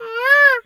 pgs/Assets/Audio/Animal_Impersonations/bird_peacock_squawk_11.wav at master
bird_peacock_squawk_11.wav